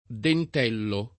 dentello [ dent $ llo ]